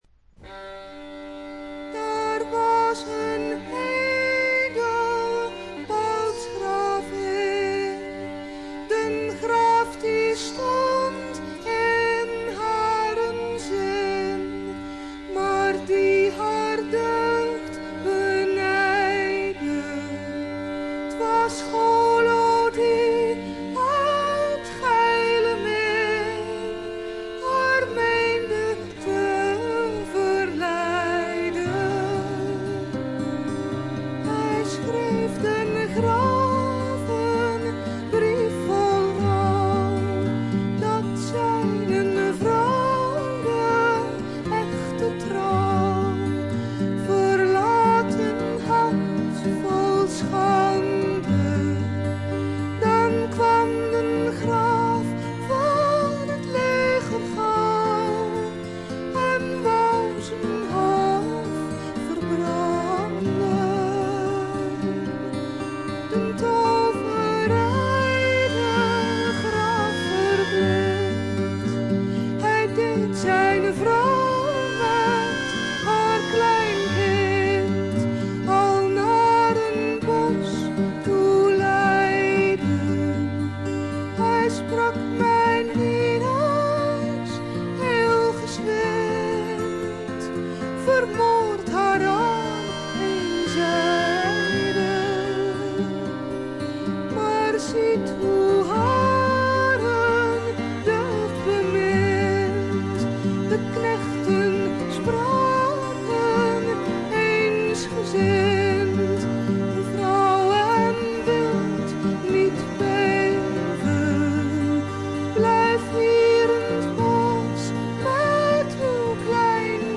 オランダのトラッド・グループ
アコースティック楽器のみのアンサンブルで美しいトラディショナル・フォークを聴かせます。
試聴曲は現品からの取り込み音源です。